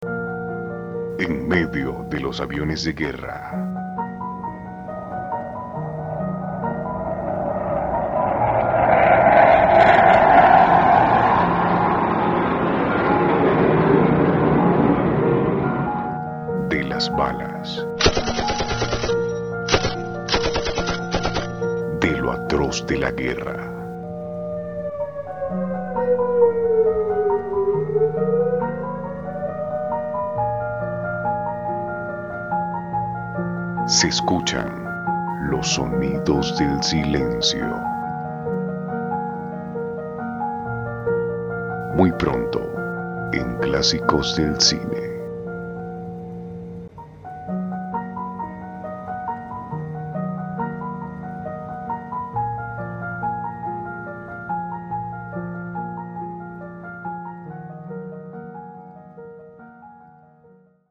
Voz grave, ideal para radio y locuciones que impliquen acento neutro y seriedad.
Sprechprobe: Sonstiges (Muttersprache):
serious voice, serious, neutral accent. Dubbing, handling of nuances. Voice of radio announcer.